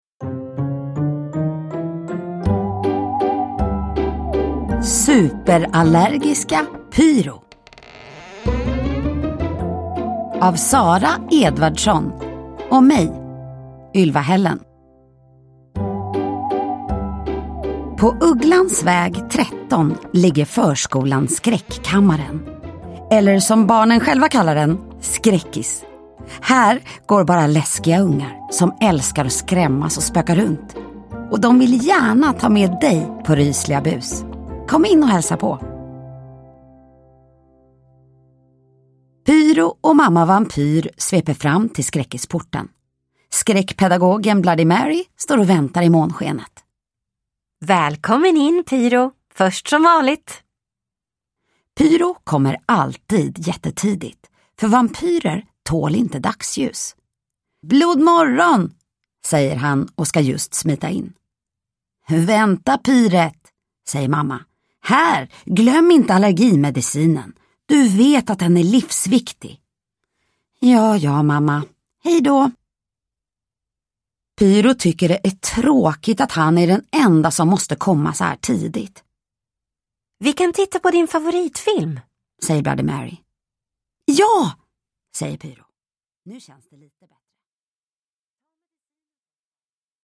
Superallergiska Pyro – Ljudbok – Laddas ner
Uppläsare: